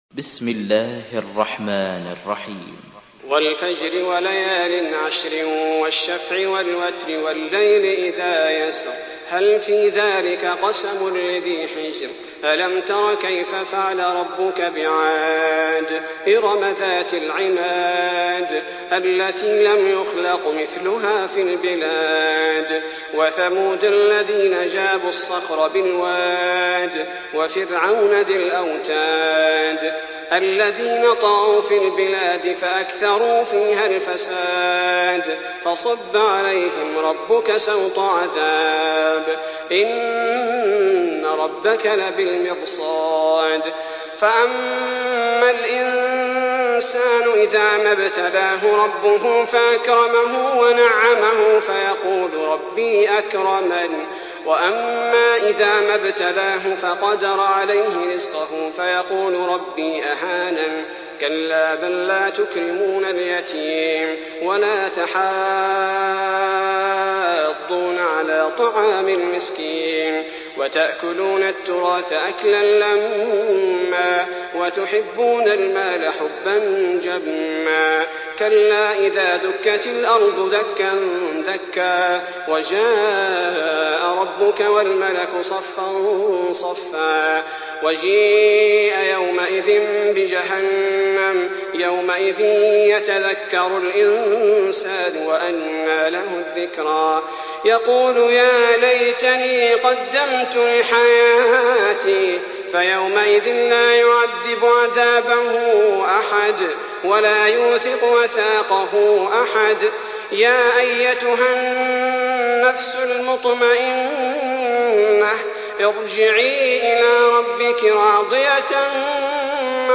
Surah আল-ফাজর MP3 by Salah Al Budair in Hafs An Asim narration.